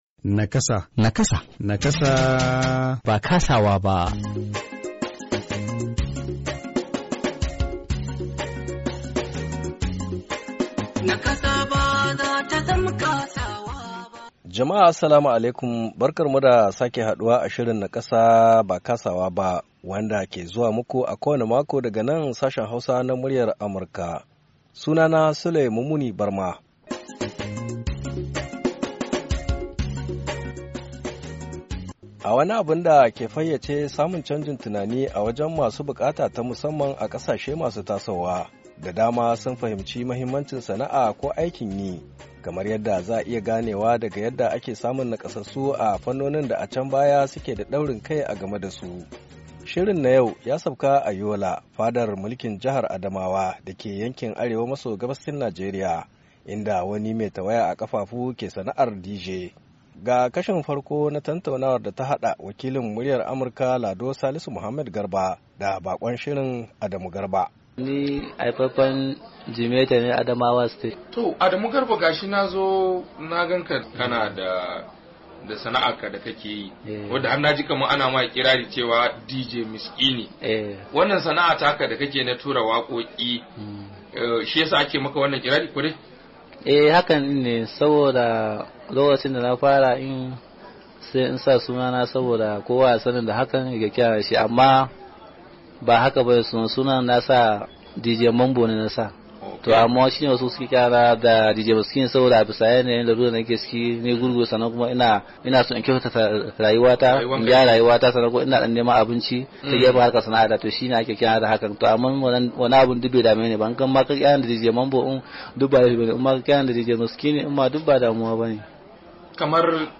NIAMEY, NIGER - A shirin Nakasa na wannan makon,mun sauka ne a Yola fadar mulkin jihar Adamawa dake yankin Arewa maso gabashin Najeriya inda muka tattauna da wani mai nakasa mai sana’ar DJ.